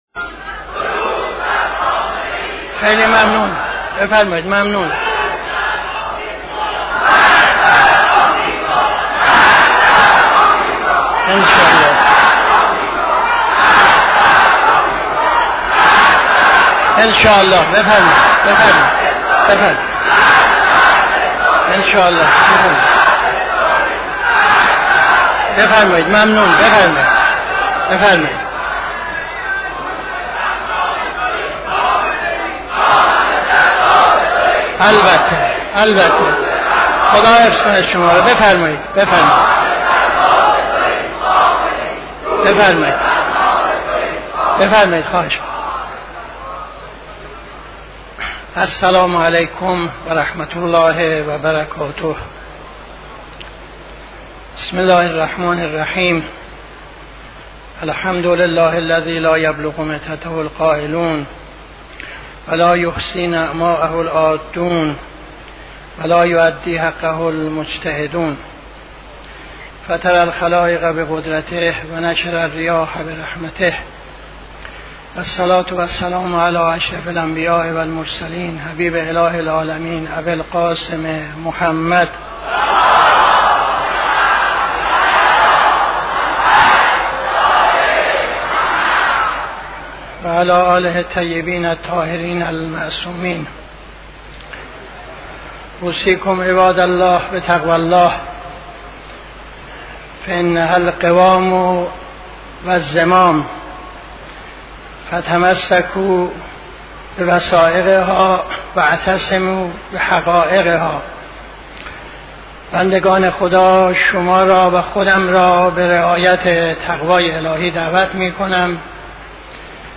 خطبه اول نماز جمعه 30-06-75